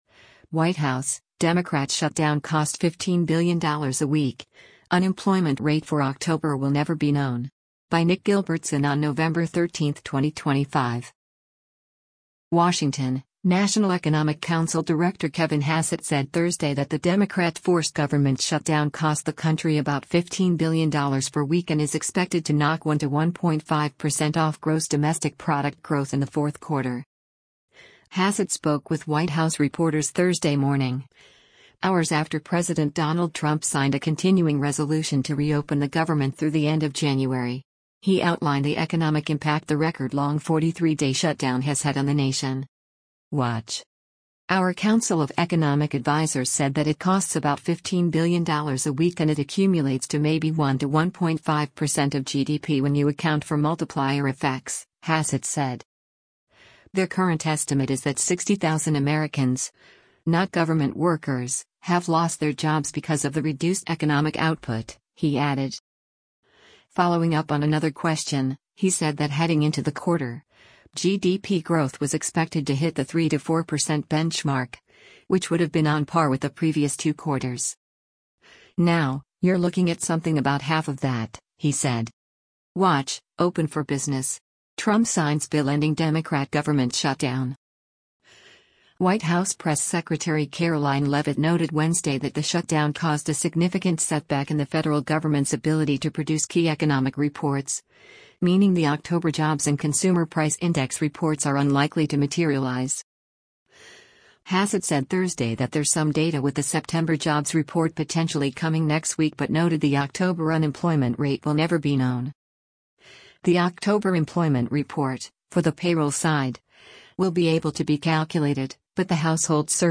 Hassett spoke with White House reporters Thursday morning, hours after President Donald Trump signed a continuing resolution to reopen the government through the end of January.